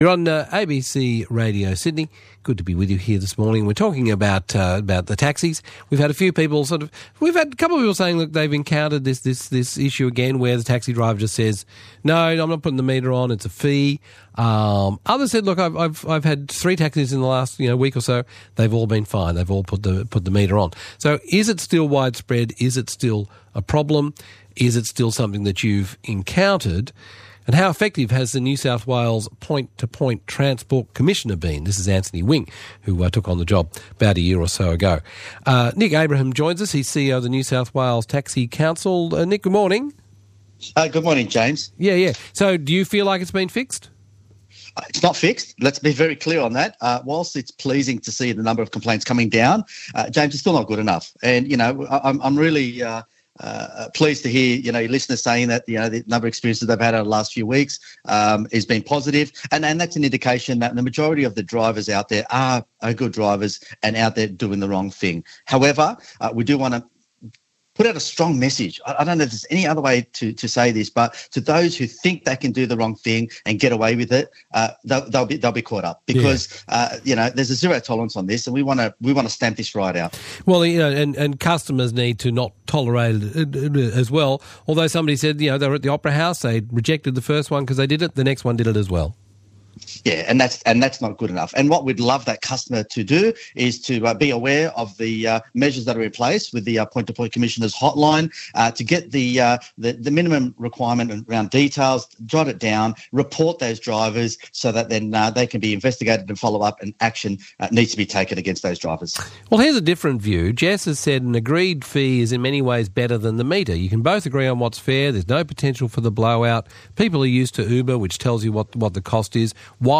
Click on Play Below to listen to the Interview -Thank You ABC Radio Sydney for the opportunity to discuss this issue.